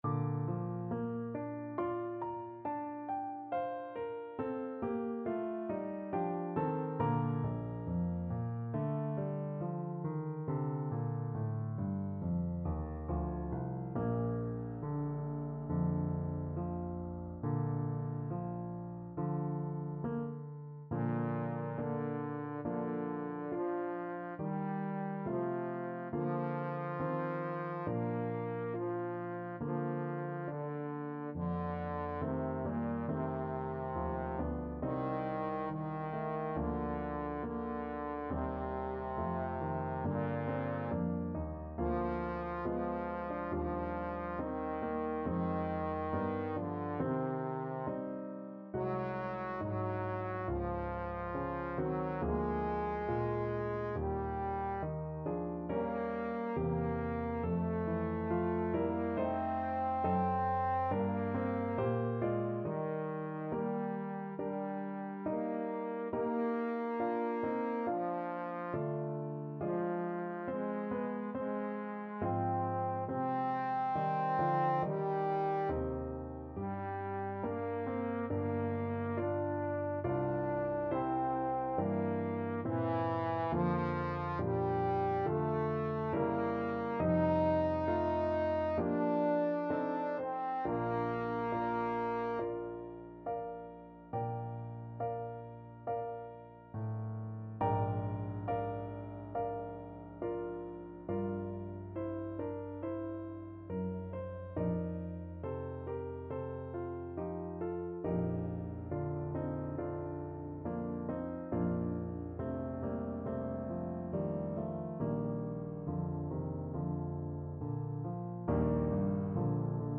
Trombone
2/2 (View more 2/2 Music)
Bb major (Sounding Pitch) (View more Bb major Music for Trombone )
Slow =c.69